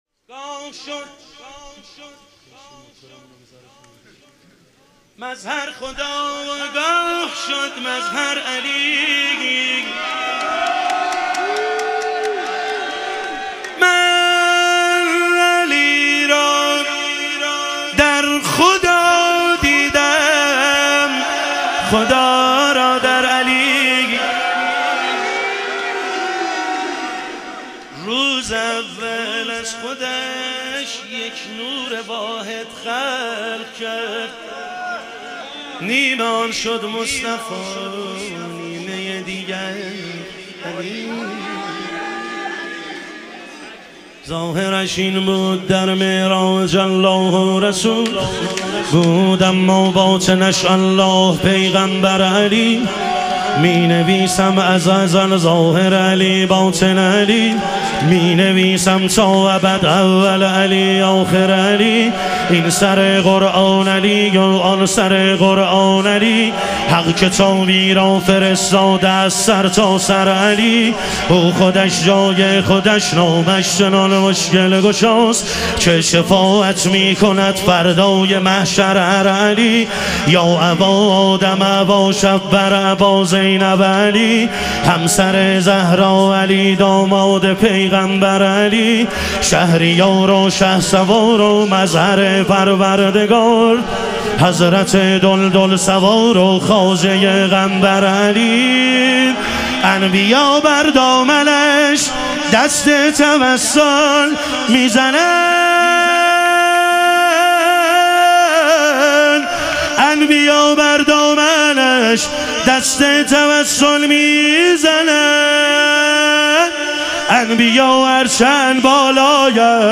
مدح و رجز
شب ظهور وجود مقدس حضرت امیرالمومنین علیه السلام